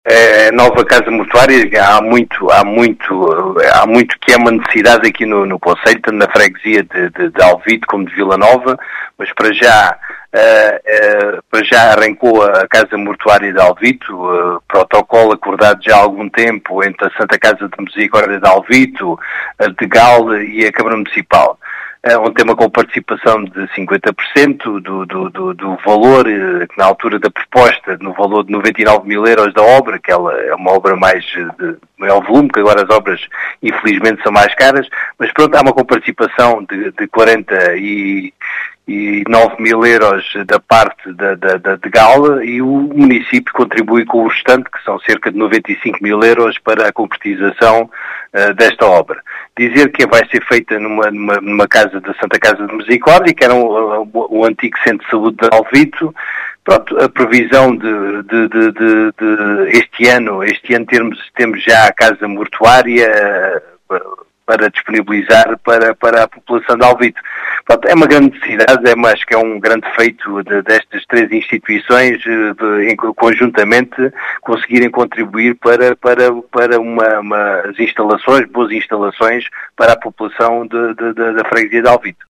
As explicações são de José Efigénio, presidente da Câmara Municipal de Alvito, que realçou a “grande necessidade” desta infraestrutura na freguesia.